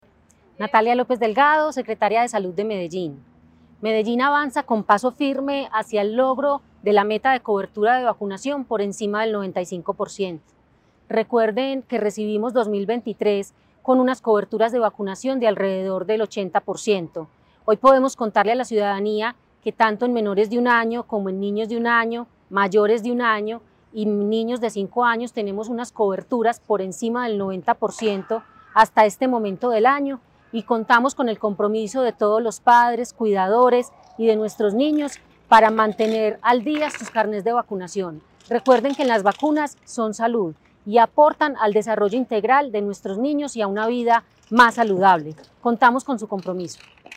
Audio Declaraciones de la secretaria de Salud, Natalia López Delgado El Distrito destaca avances significativos en las coberturas de vacunación infantil, con una tendencia positiva y sostenida durante los últimos dos años.
Audio-Declaraciones-de-la-secretaria-de-Salud-Natalia-Lopez-Delgado-1.mp3